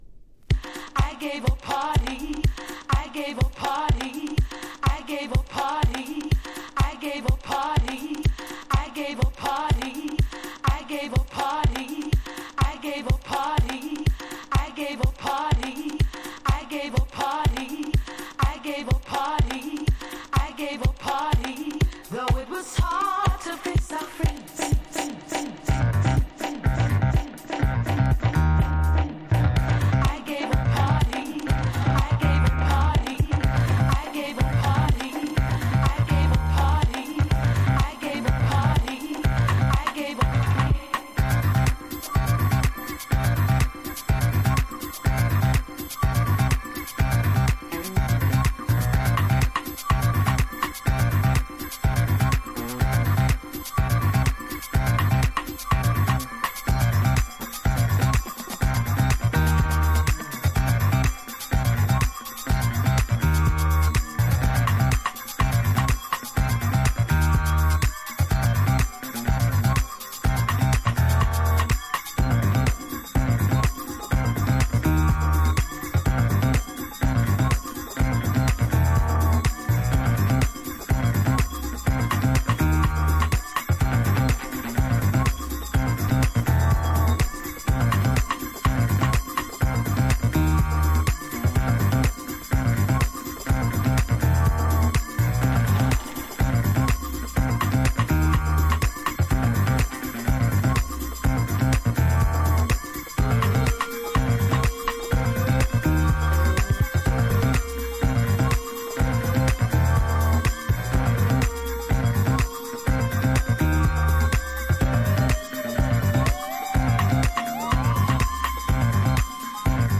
ファンクなベース・ラインのワン・ループが最高!!
NU-DISCO / RE-EDIT